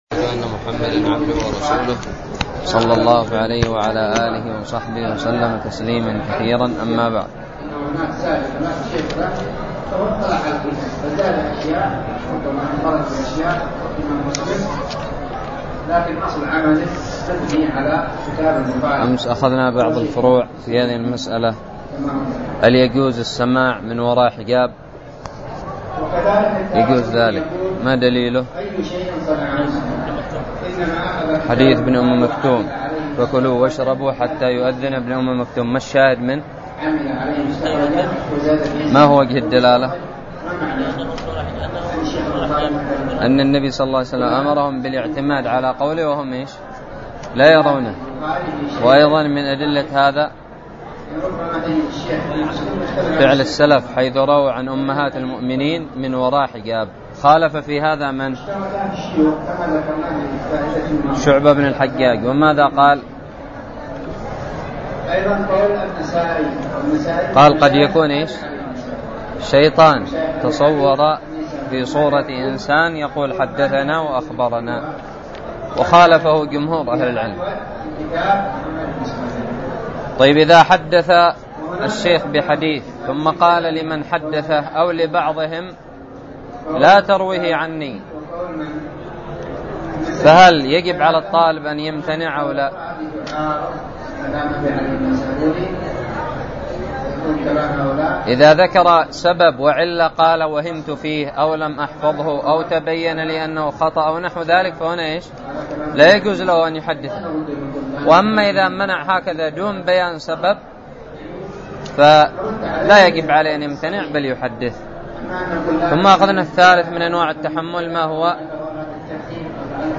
الدرس الرابع والثلاثون من شرح كتاب الباعث الحثيث
ألقيت بدار الحديث السلفية للعلوم الشرعية بالضالع